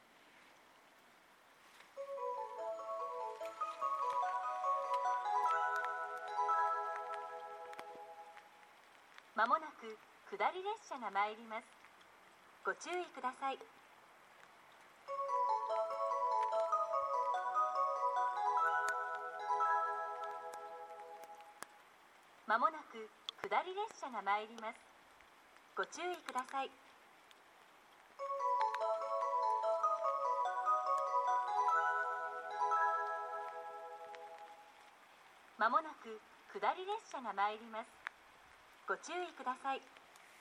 この駅では接近放送が設置されています。
２番線仙石線
接近放送普通　石巻行き接近放送です。